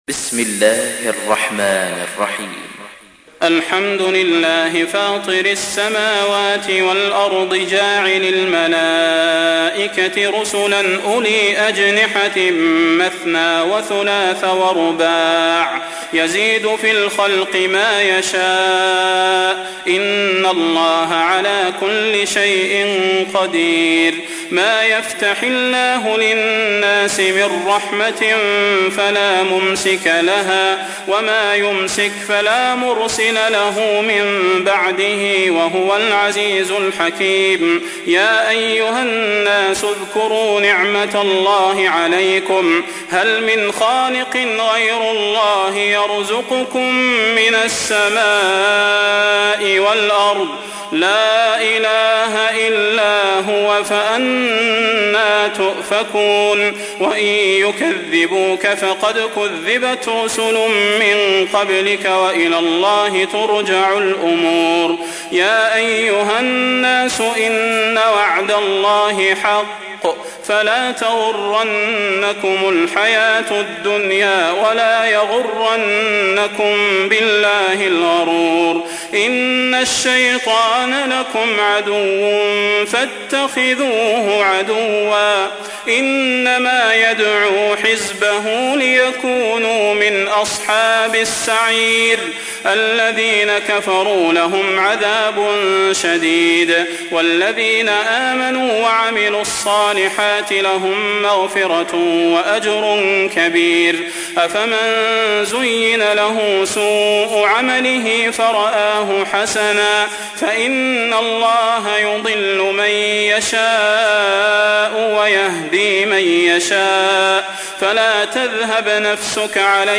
تحميل : 35. سورة فاطر / القارئ صلاح البدير / القرآن الكريم / موقع يا حسين